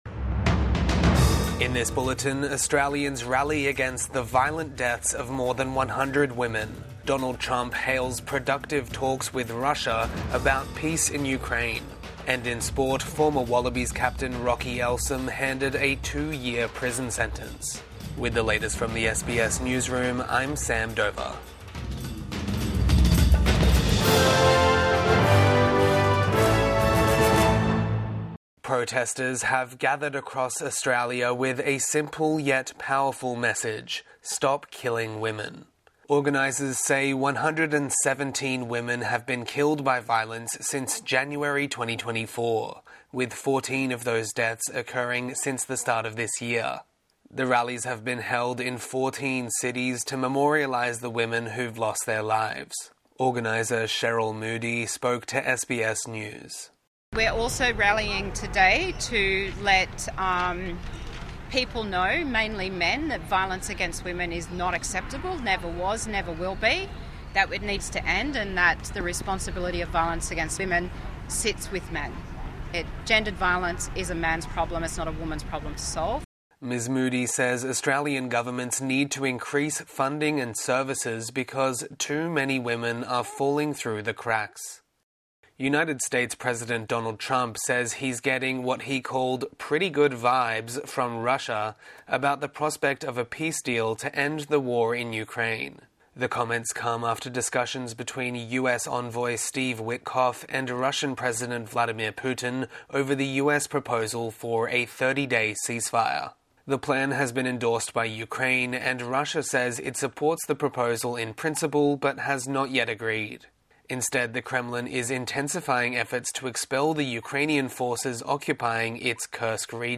Evening News Bulletin 15 March 2025